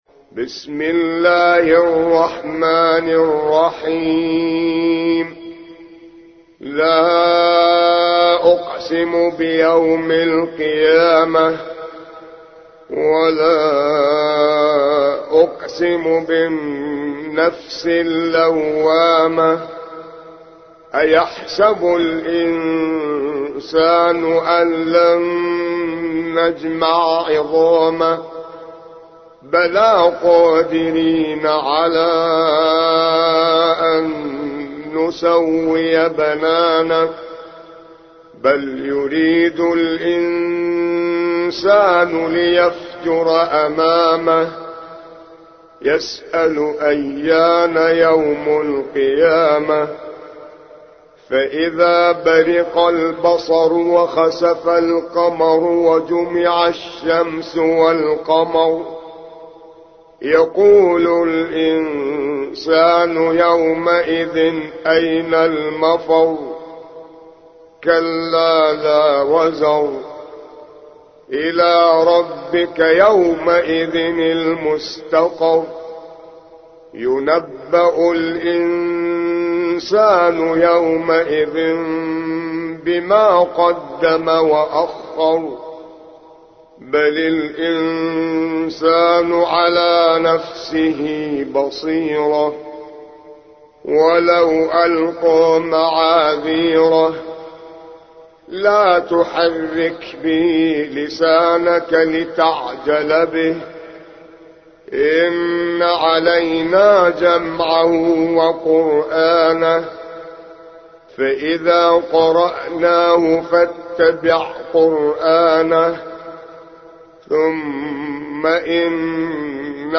75. سورة القيامة / القارئ